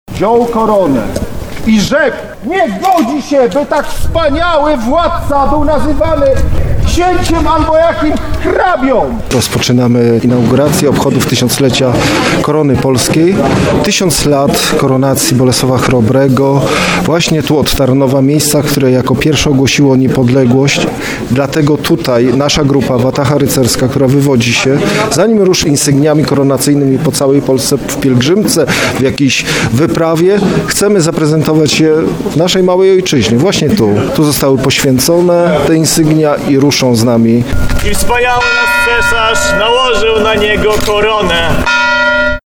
Koronacja Bolesława Chrobrego na rynku w Tarnowie